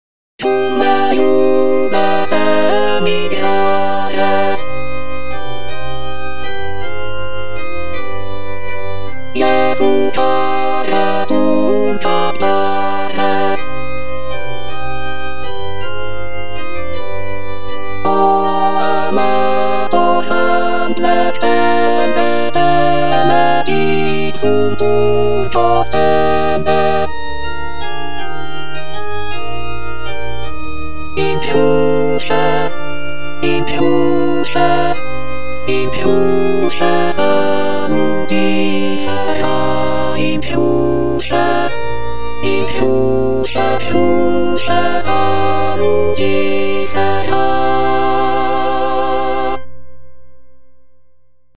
D. BUXTEHUDE - MEMBRA JESU NOSTRI PATIENTIS SANCTISSIMA BUXWV75 Cum me jubes emigrare - Alto (Emphasised voice and other voices) Ads stop: auto-stop